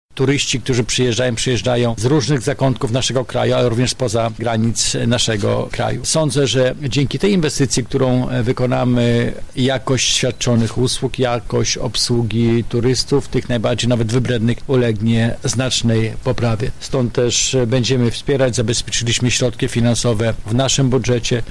O powodach przyznania dofinansowania mówi wicemarszałek województwa Krzysztof Grabczuk: